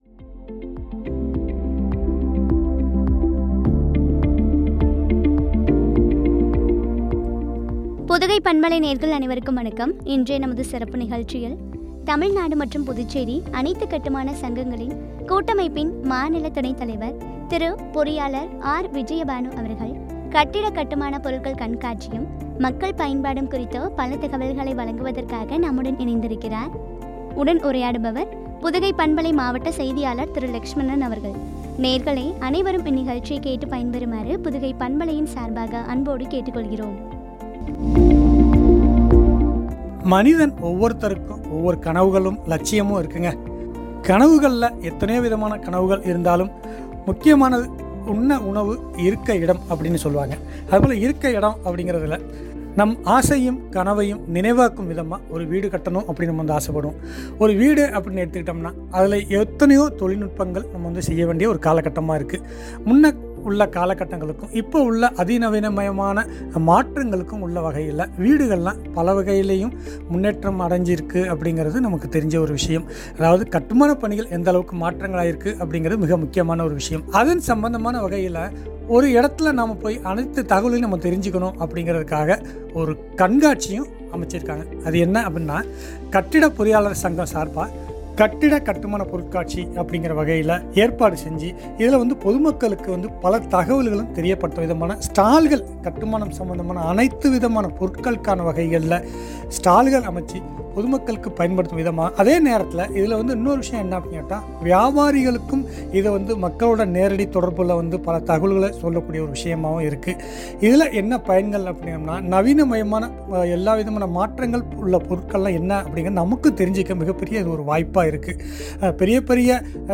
மக்கள் பயன்பாடும் பற்றிய உரையாடல்.